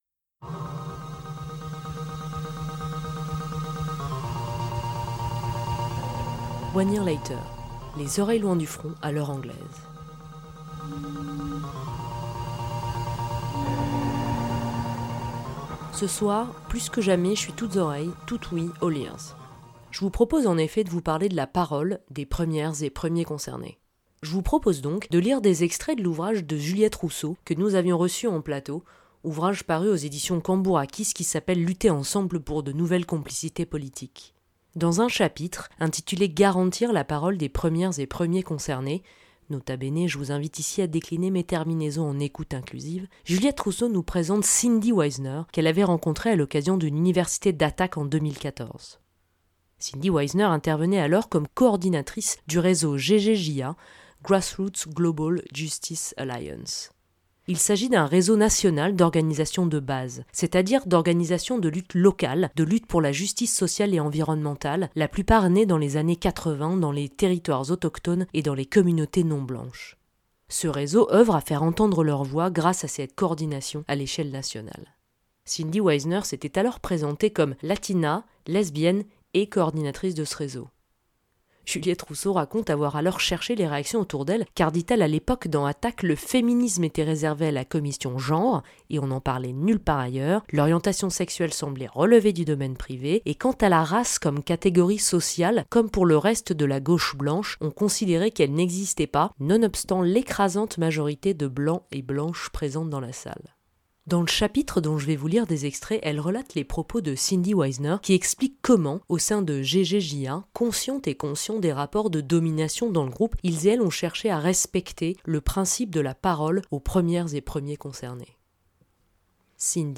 Emission radiophonique en direct tous les mercredis de 19h à 20H30